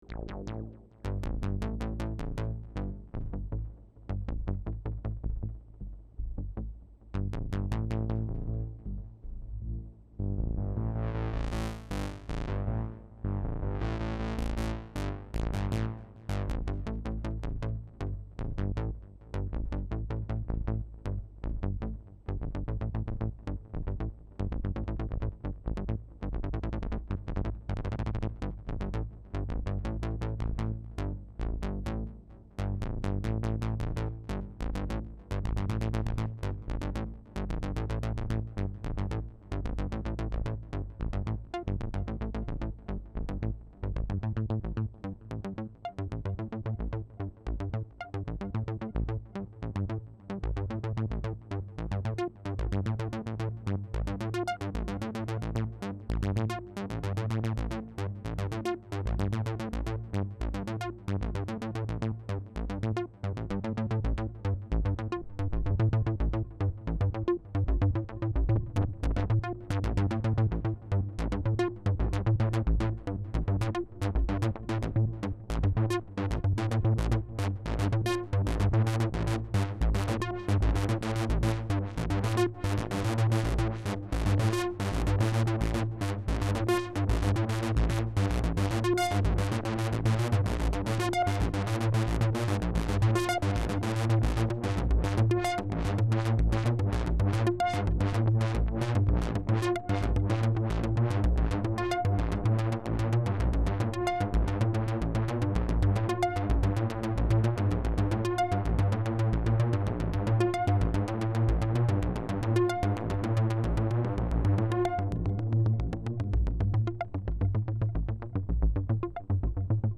SOUND everything classic: pads, basses and sweeps
Quick & Dirty Audio Demo 2
Audio Demo Jp08 B